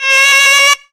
HARM SQUEAL.wav